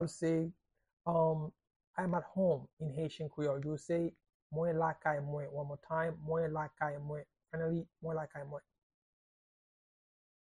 How-to-say-I-am-at-home-in-Haitian-Creole-–-Mwen-lakay-mwen-pronunciation-by-a-native-Haitian-speaker.mp3